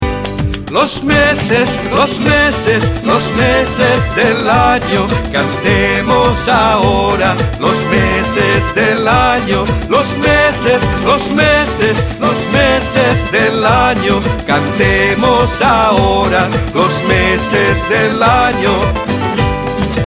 high-energy Spanish song